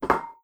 Impacts
clamour5.wav